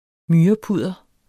Udtale [ ˈmyːʌˌpuðˀʌ ]